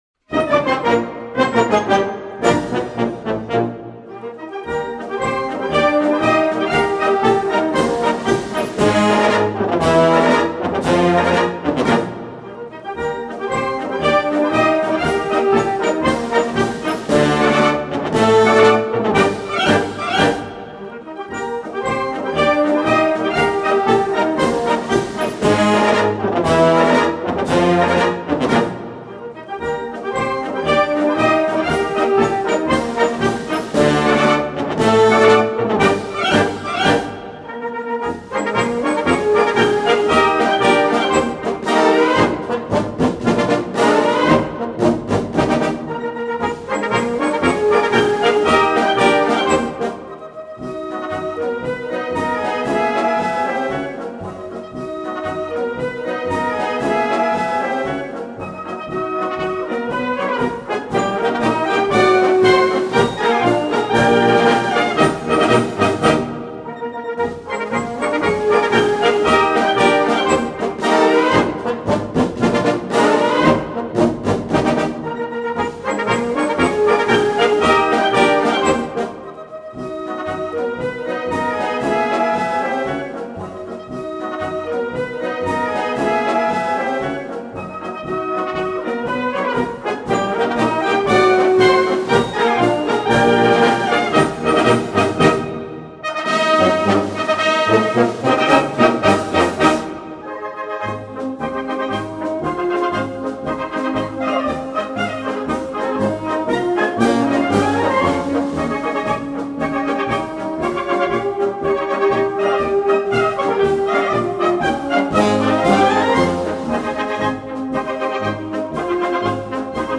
Gattung: Marsch
Besetzung: Blasorchester
Grandioser Konzertmarsch
für Blasorchester